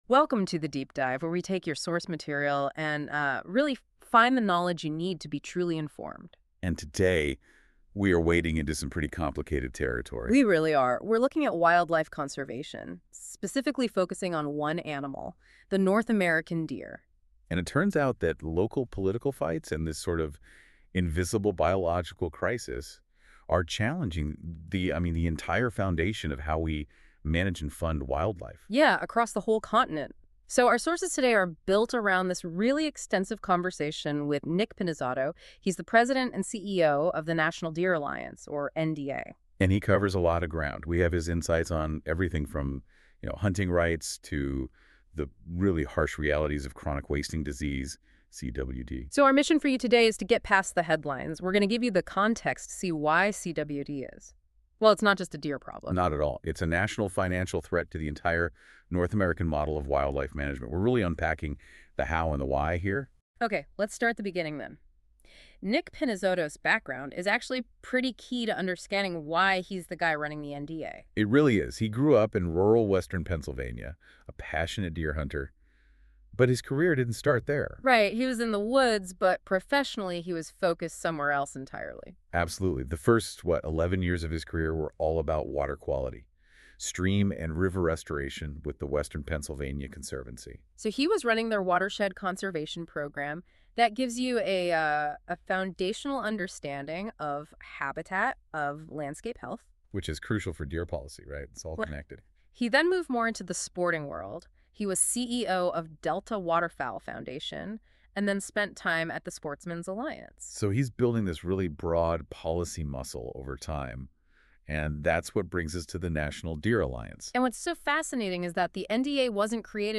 AI generated summary The source material, featuring insights from the National Deer Alliance, examines how Chronic Wasting Disease (CWD) presents an existential threat to wildlife conservation across North America.